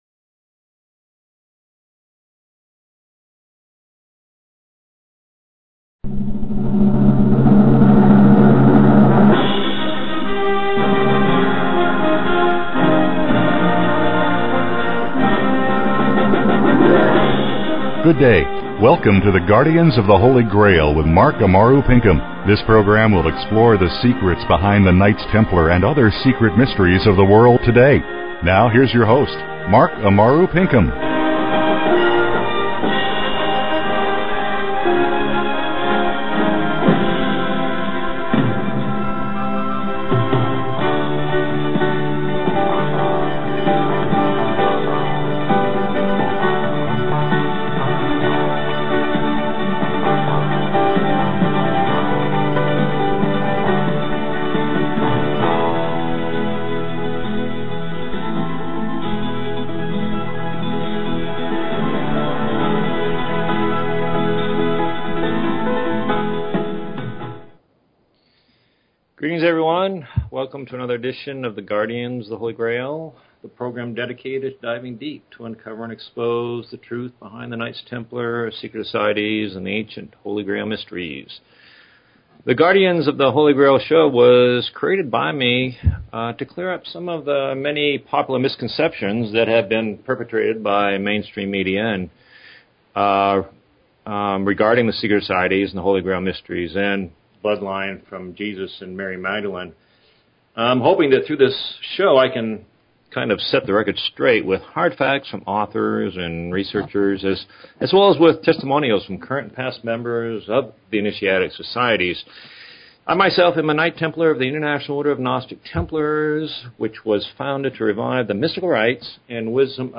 Talk Show Episode, Audio Podcast, The_Guardians_of_the_Holy_Grail and Courtesy of BBS Radio on , show guests , about , categorized as